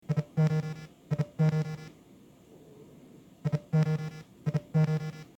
Звуки вибрации смартфонов
На этой странице собрана коллекция звуков вибрации различных смартфонов.